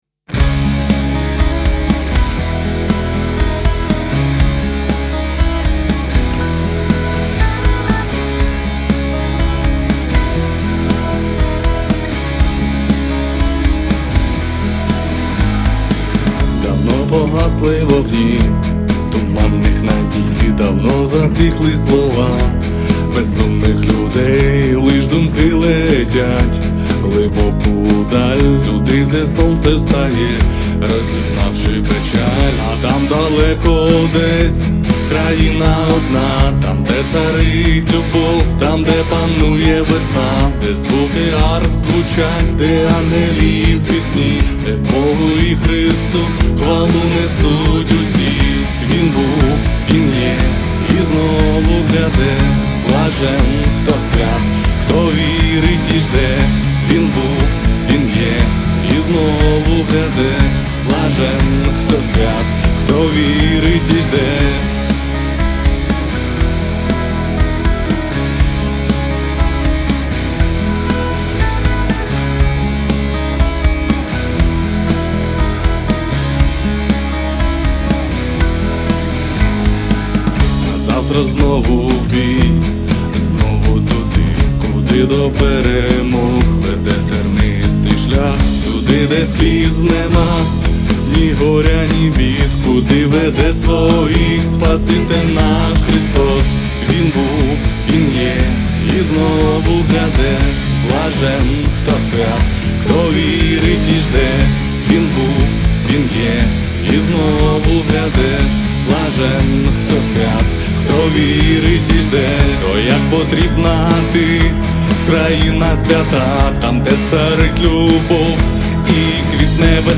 295 просмотров 268 прослушиваний 25 скачиваний BPM: 125